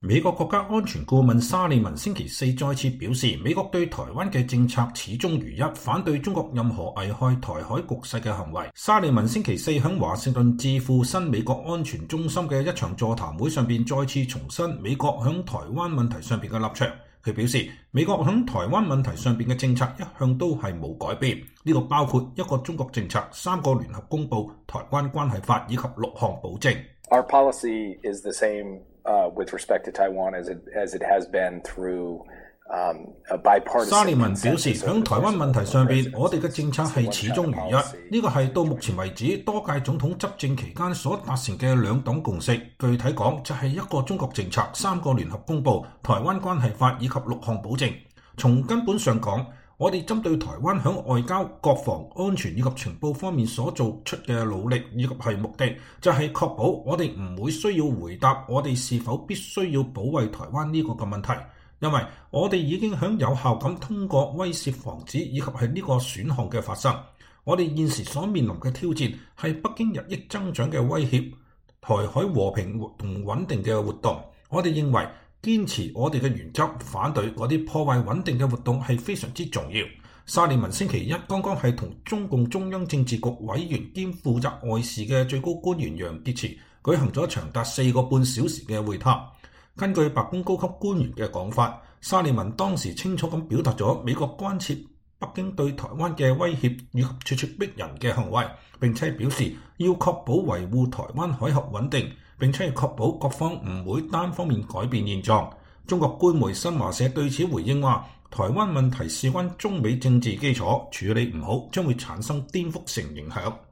沙利文星期四在華盛頓智庫新美國安全中心(Center for a New American Security)的一場座談會上再次重申了美國在台灣問題上的立場。